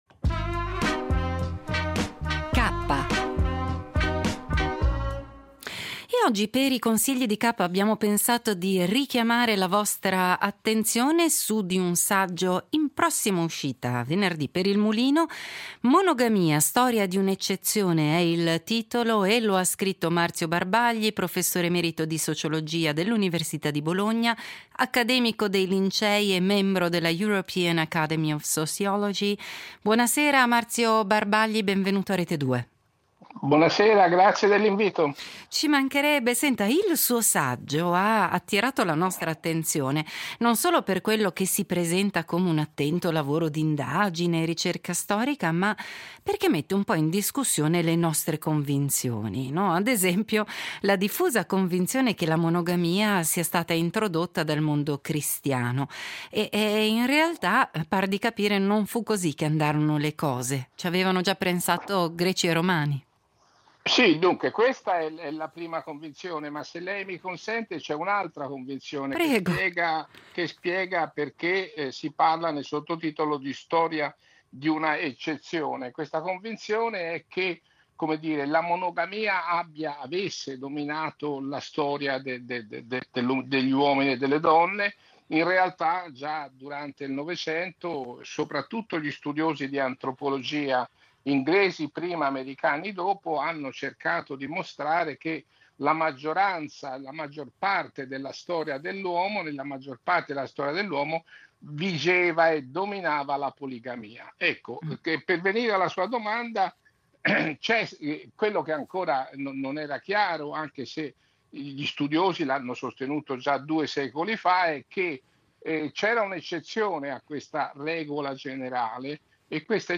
Intervista a Marzio Barbagli